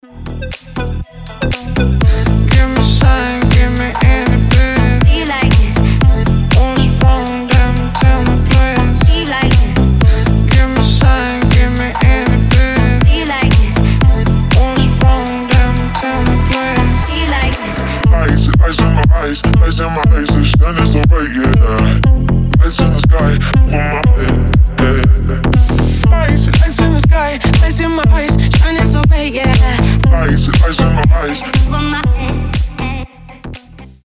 • Качество: 320, Stereo
deep house
басы
Brazilian bass
качающие
G-House
Крутой саунд